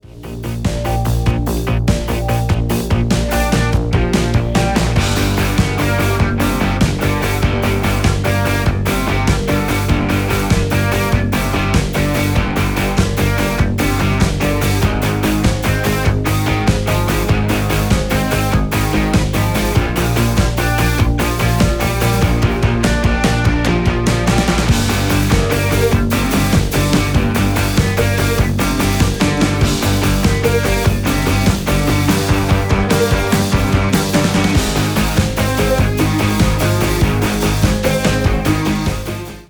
Рок Металл
без слов